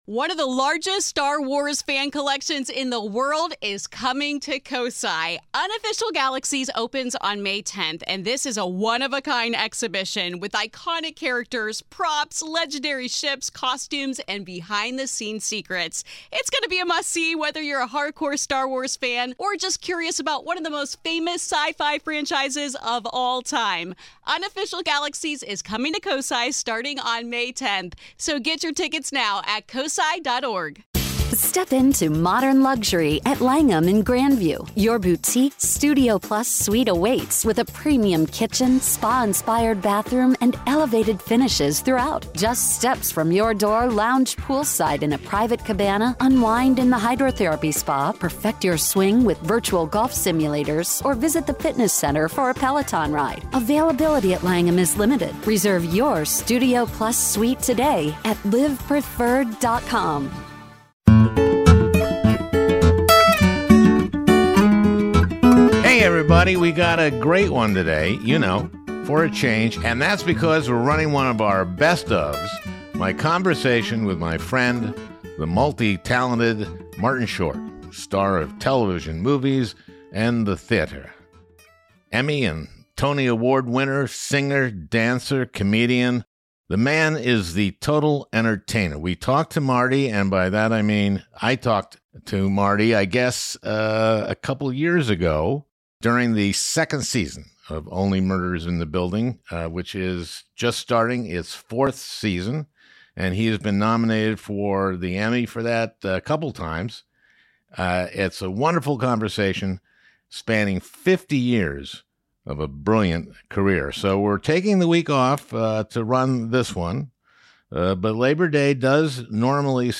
So we're replaying this interview with comedian and actor Martin Short!